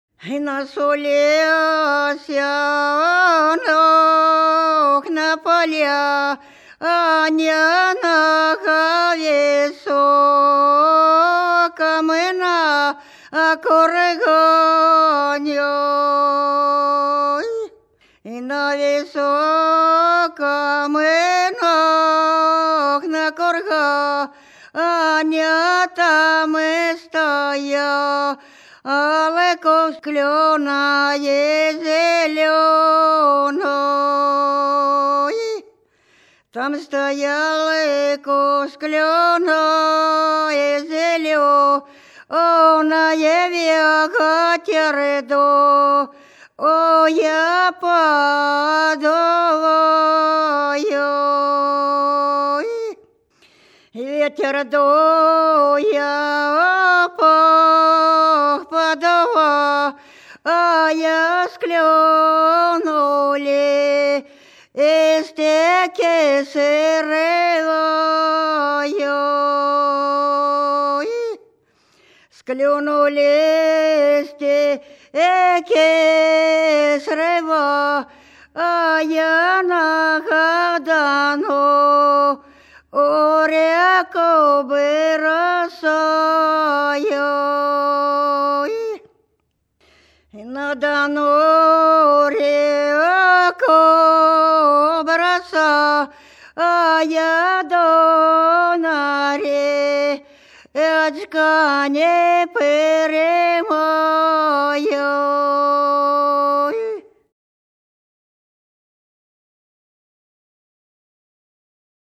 В нас у лесе на поляне — весновая песня.
запевала
подголосок